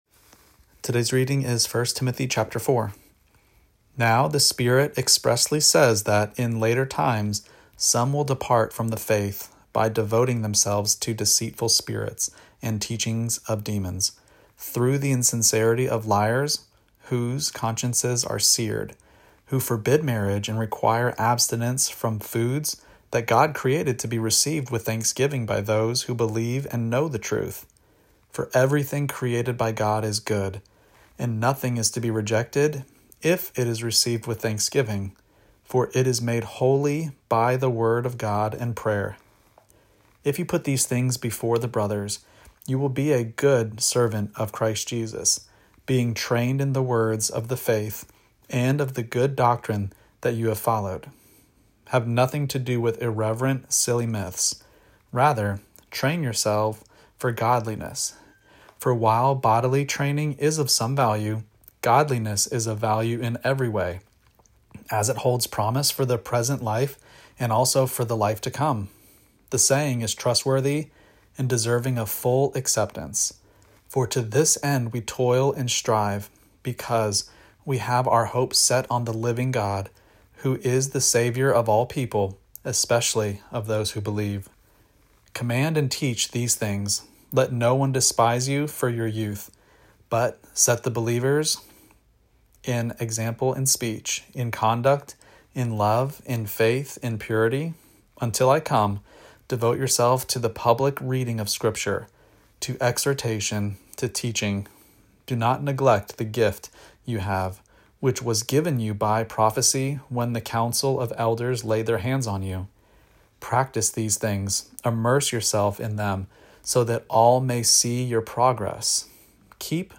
Daily Bible Reading (ESV) October 24: 1 Timothy 4 Play Episode Pause Episode Mute/Unmute Episode Rewind 10 Seconds 1x Fast Forward 30 seconds 00:00 / 2:11 Subscribe Share Apple Podcasts Spotify RSS Feed Share Link Embed